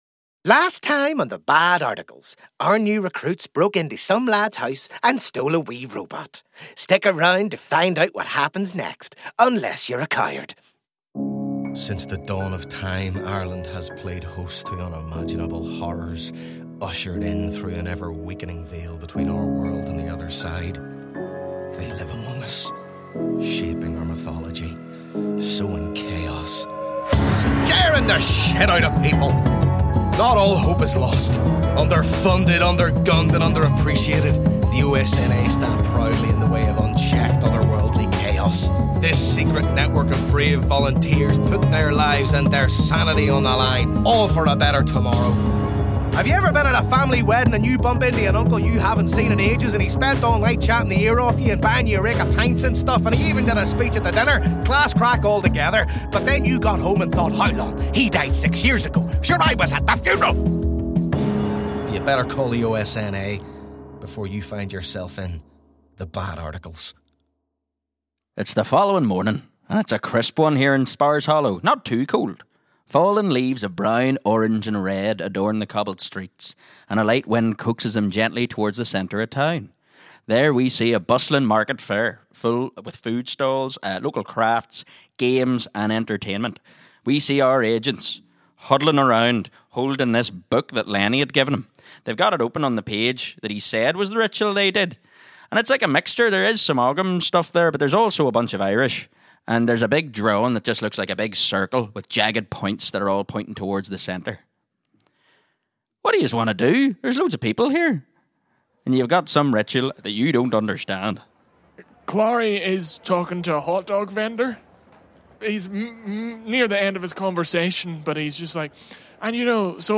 CONTENT WARNING While the tone of the show is light-hearted and comedic, it contains references to lost children, as well as scenes featuring mild horror elements and light gore sound effects.